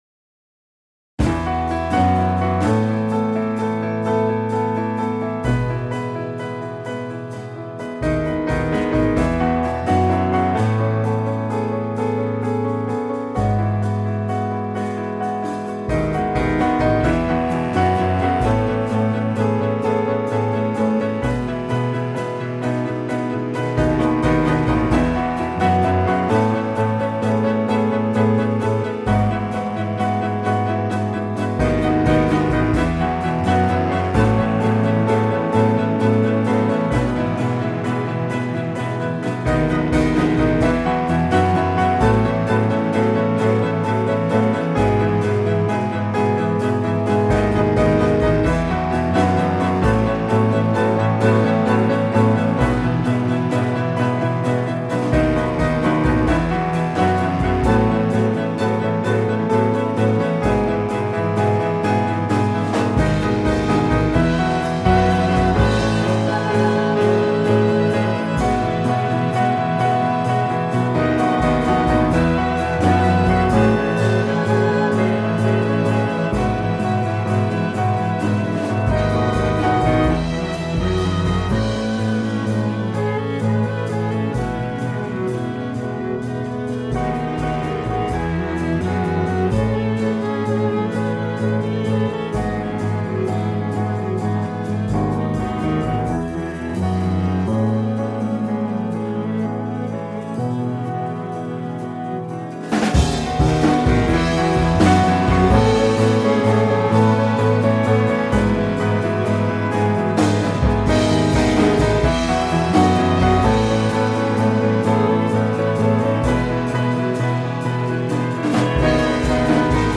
the album features 15 guest musicians